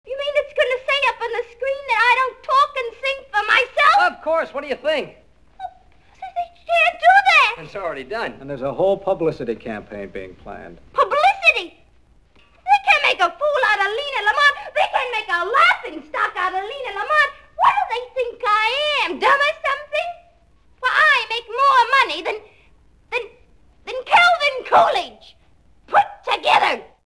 My favorite funny moviescenes on wav file!